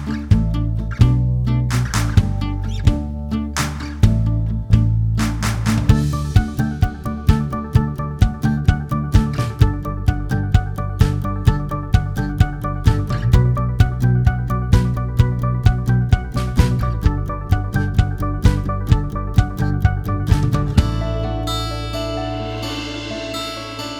for solo singer Country (Female) 3:08 Buy £1.50